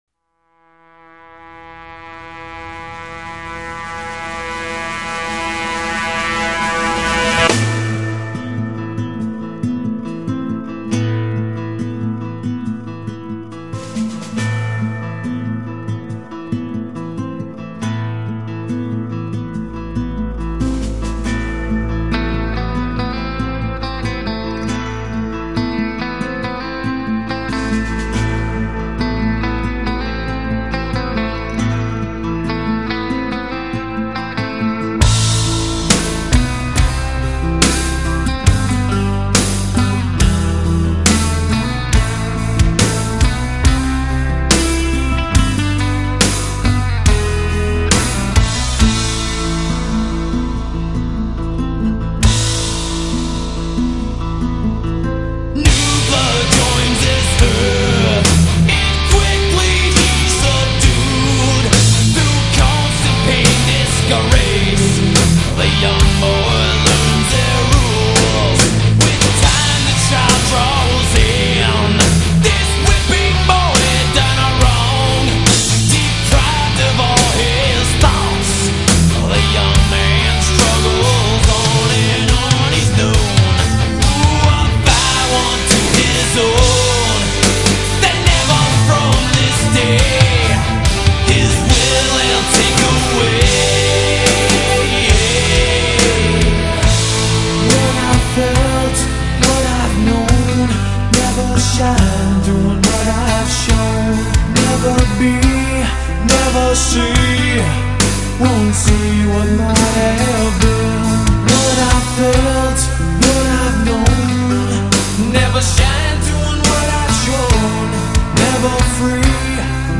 "АКУЛЫ" настоящего рока